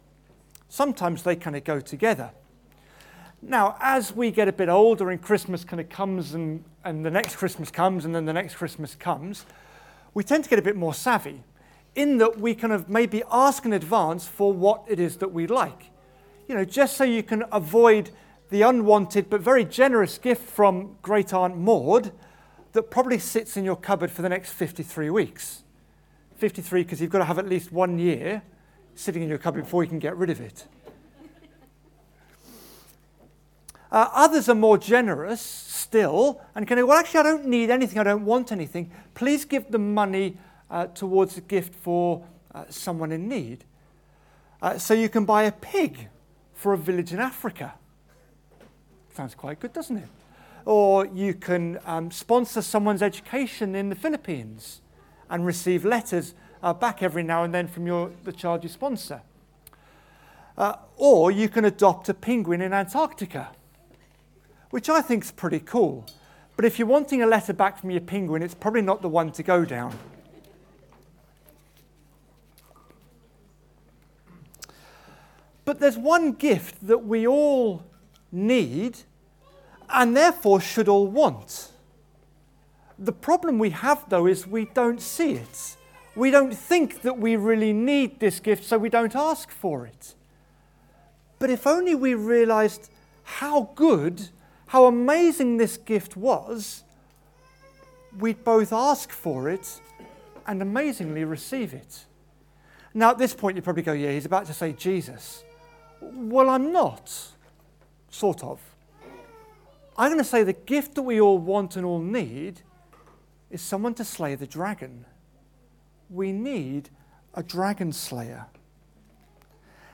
A dragon in the nativity | Carol Service 2024